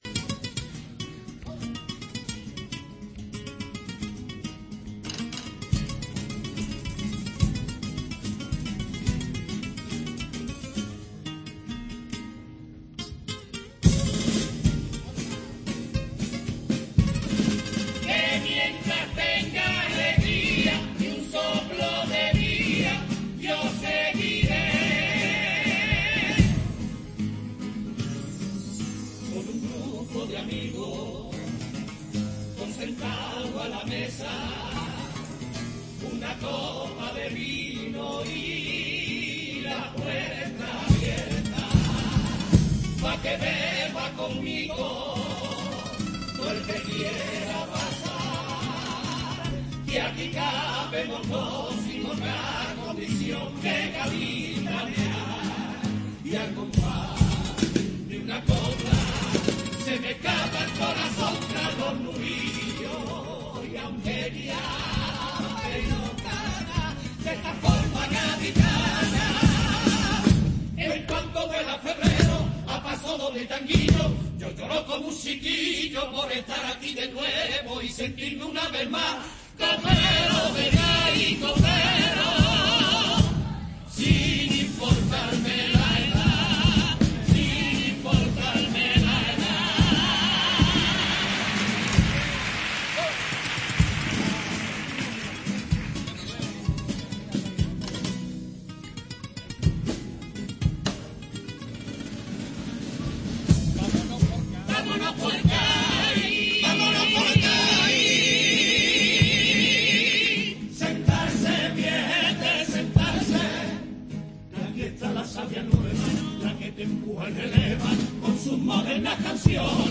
Comparsa "ley de vida"